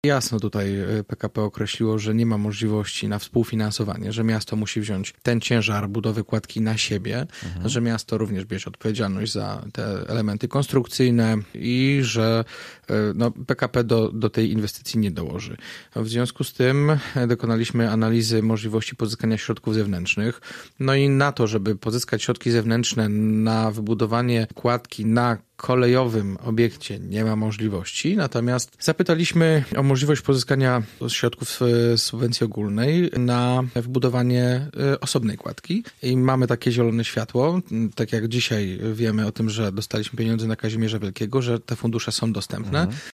Inwestycję zapowiedział w Radiu Gorzów prezydent Jacek Wójcicki. Miasto chce ją sfinansować w połowie z rządowych pieniędzy: